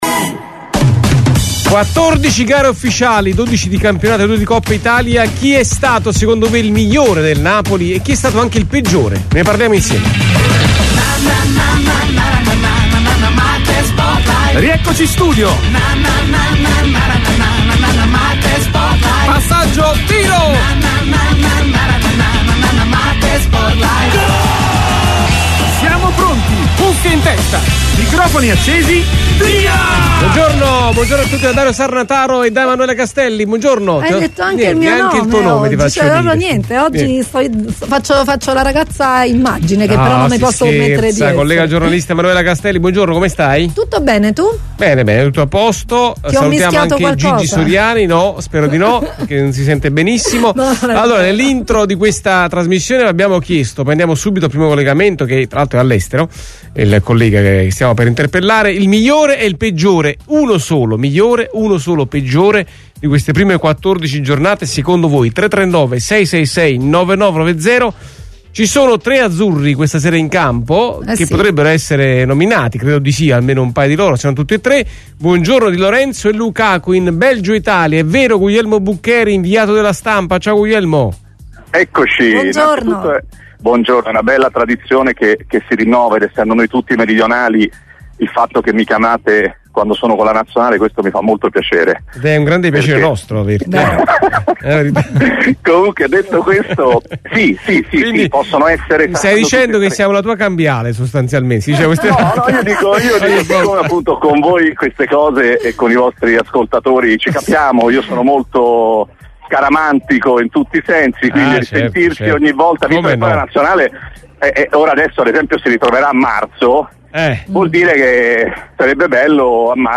MARTE SPORT LIVE è UNA TRASMISSIONE SPORTIVA, UN TALK CON OSPITI PRESTIGIOSI, OPINIONISTI COMPETENTI, EX TECNICI E GIOCATORI DI VALORE, GIORNALISTI IN CARRIERA E PROTAGONISTI DEL CALCIO ITALIANO E INTERNAZIONALE.